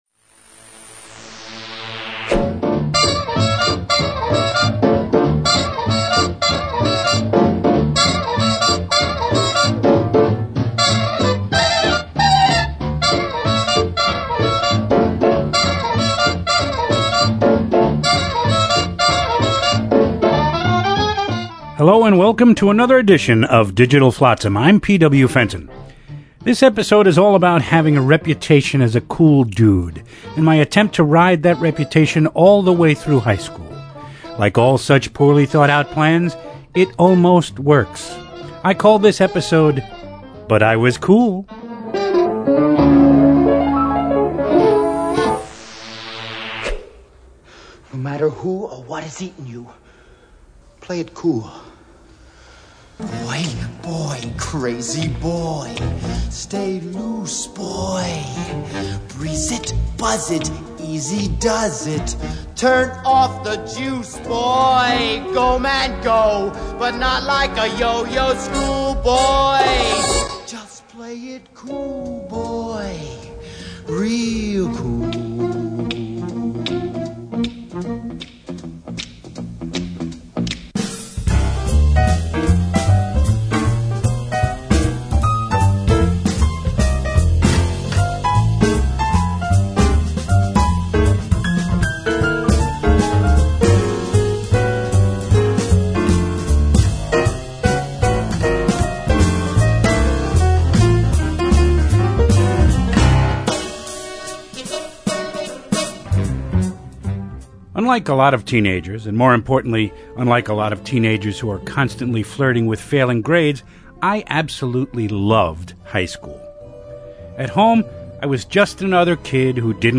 We are proud to offer these great spoken word pieces again.